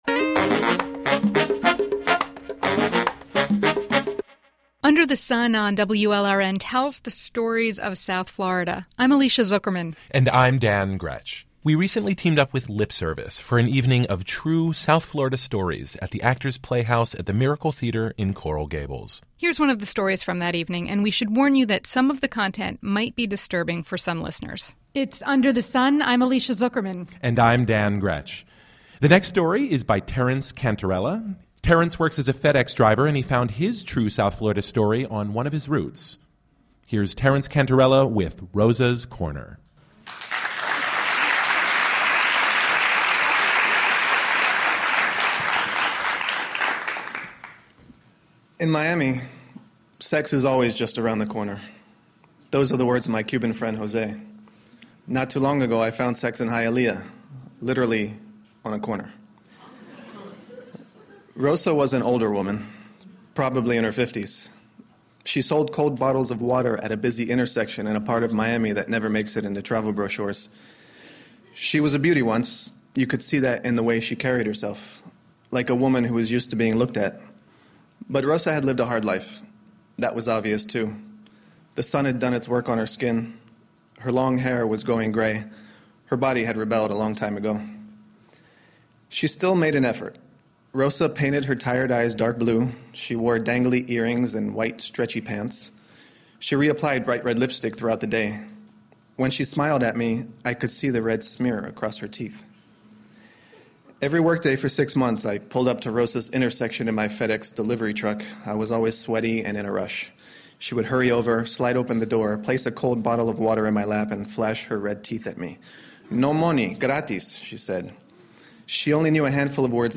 Read in front of a live audience in Miami and broadcasted on June 4th, 2011 on South Florida’s NPR station, WLRN